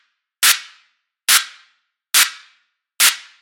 鼓点击打节拍音效
描述：鼓点击打节拍音效。
标签： house 混合 配音 小军鼓 击打 杜比
声道立体声